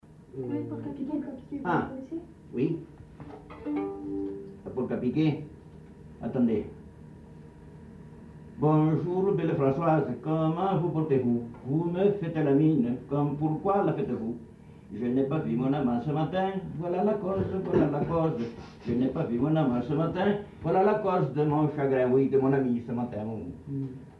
Genre : chant
Effectif : 1
Type de voix : voix d'homme
Production du son : chanté
Danse : polka piquée